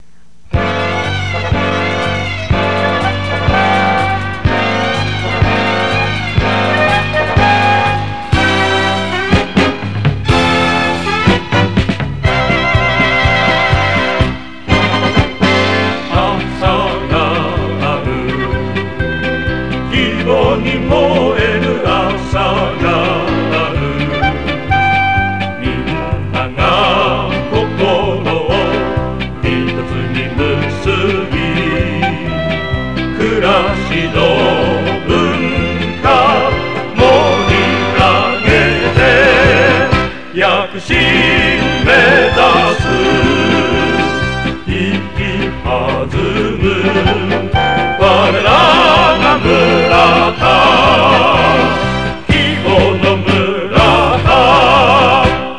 List_R02 企业歌曲《为希望而燃烧》（1979年）